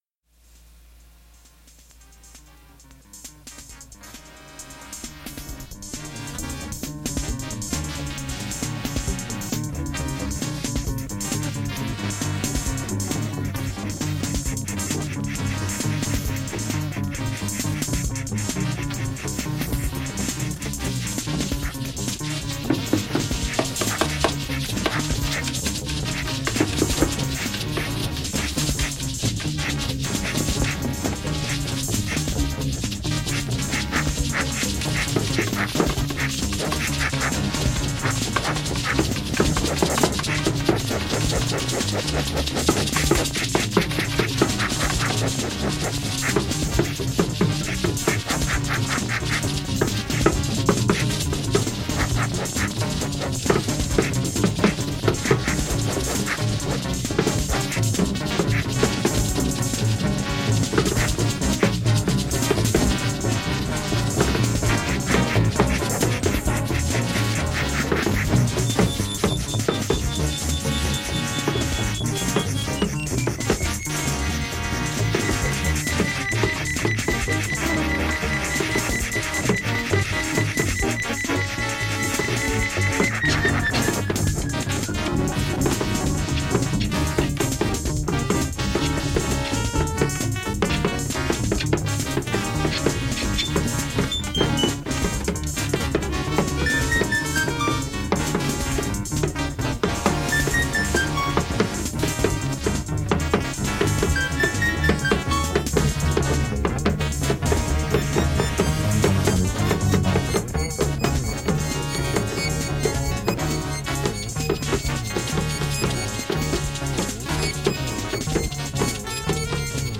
SIDnth I Keyboard and Joystick Percussion Synthesizer.
Electric Bass